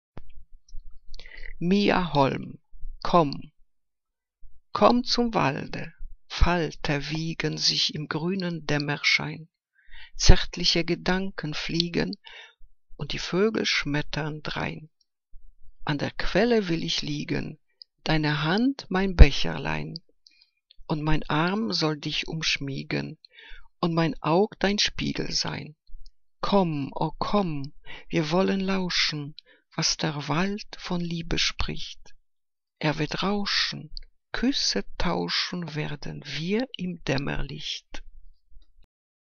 Ausgewählte Liebesgedichte